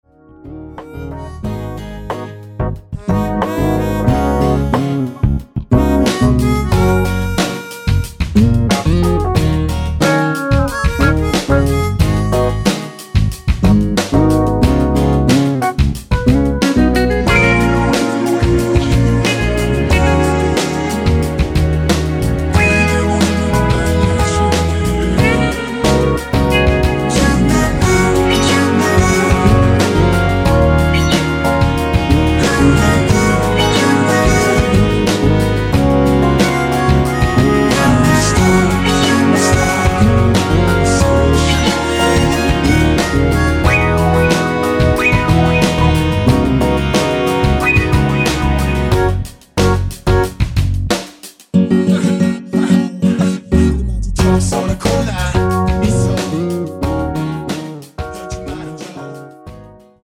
-2)내린 코러스 포함된 MR 입니다.
F#
앞부분30초, 뒷부분30초씩 편집해서 올려 드리고 있습니다.
중간에 음이 끈어지고 다시 나오는 이유는